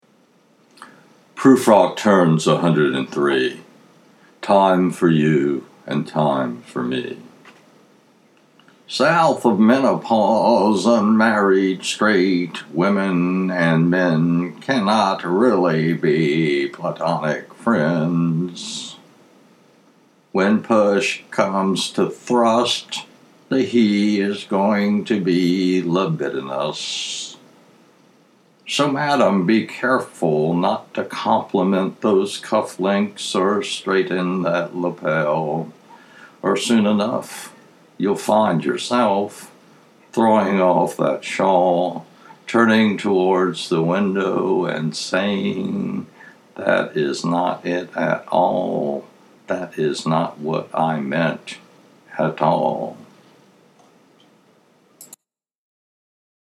[to hear J. Alfred read his poem, click the arrow below]